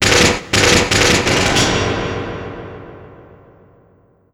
UZI 2     -L.wav